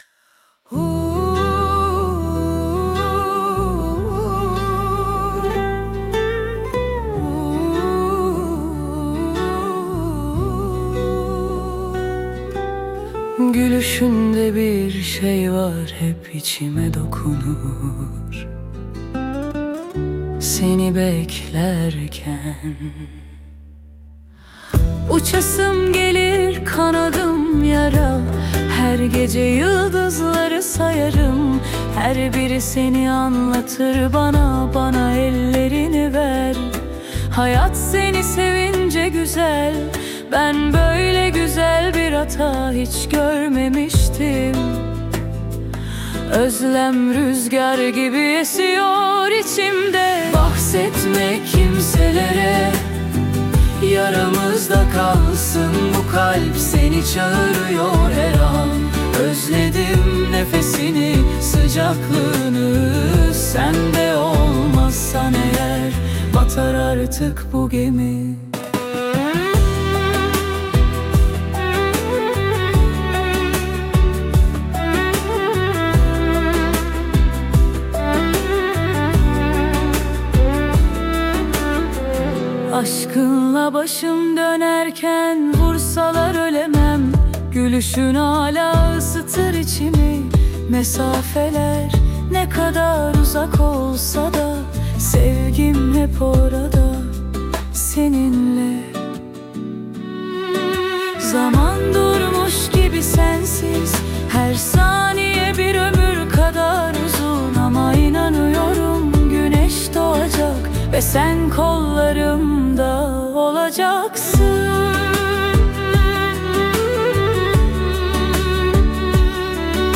AI ile üretilen 58+ özgün müziği keşfedin
🎤 Vokalli 02.12.2025